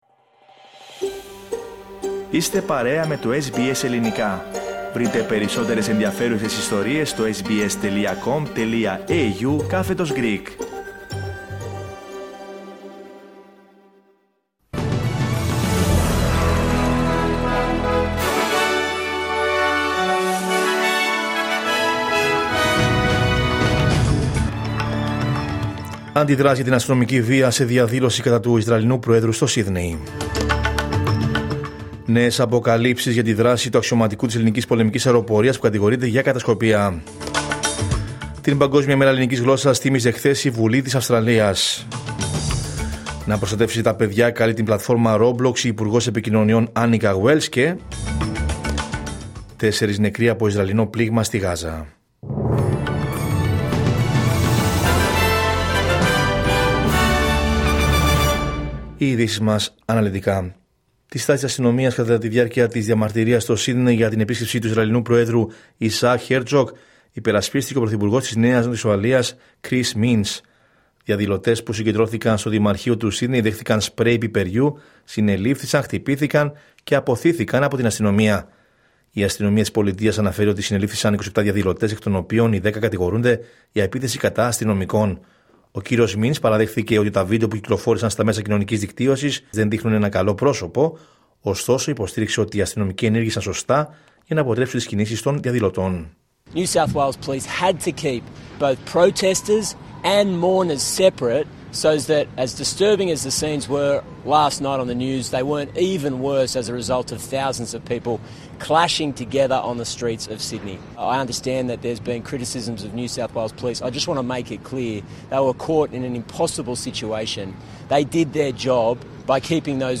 Ειδήσεις: Τρίτη 10 Φεβρουαρίου 2026